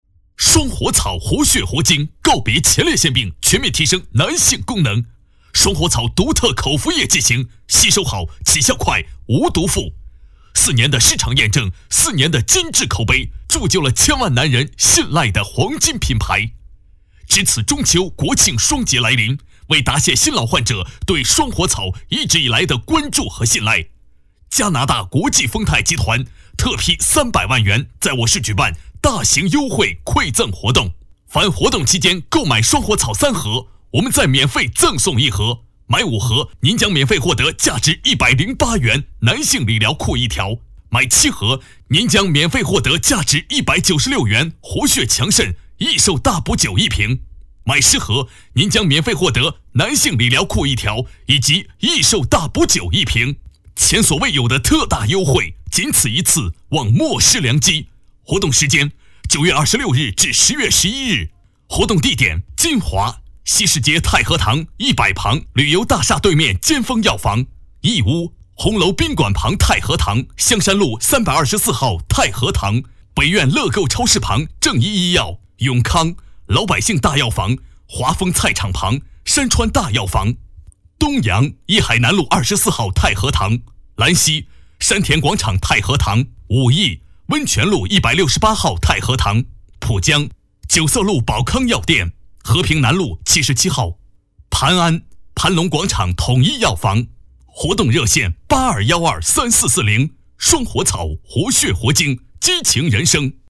电视购物配音